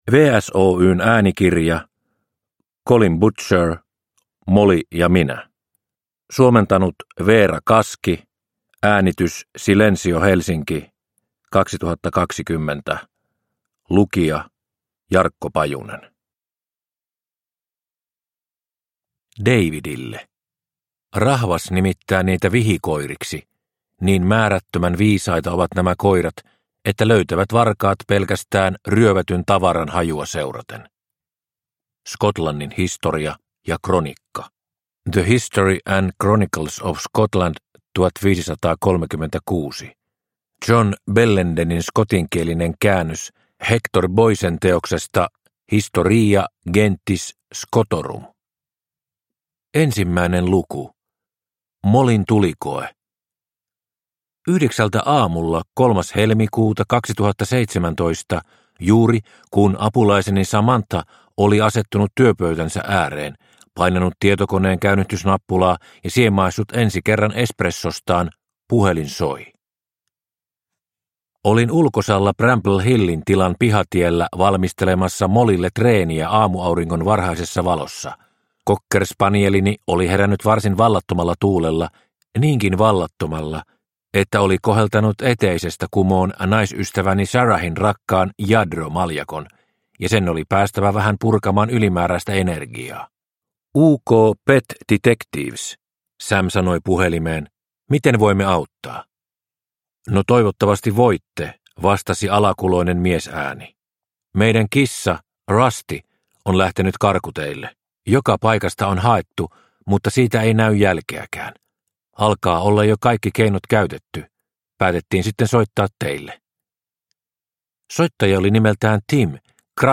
Molly ja minä – Ljudbok – Laddas ner